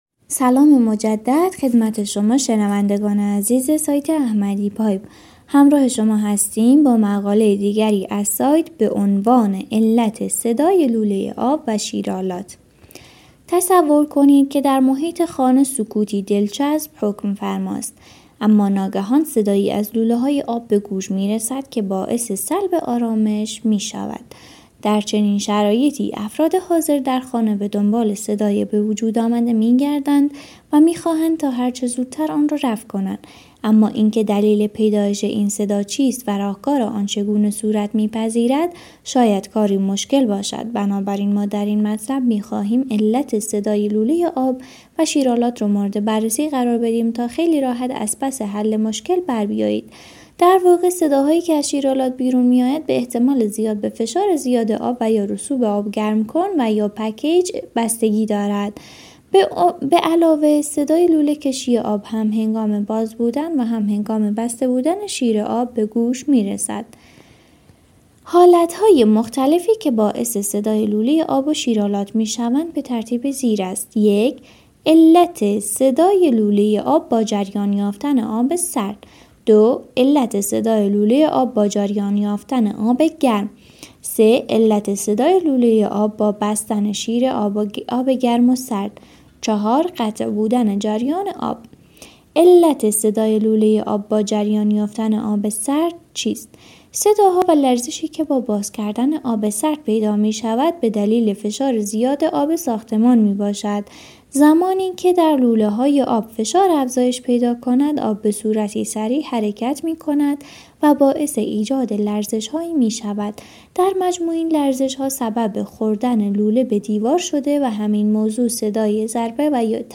تمامی متن مقاله علت صدای لوله آب و شیرآلات چیست؟ را می توانید به صورت pdf و در قالب یک پادکست گوش دهید.